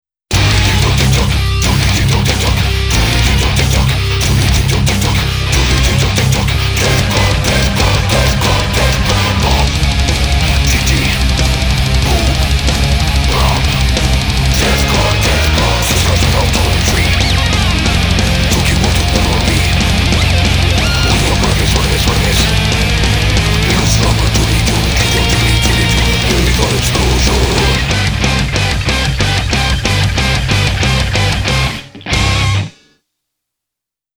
• Качество: 320, Stereo
брутальные
Death Metal
эпичные
melodic death metal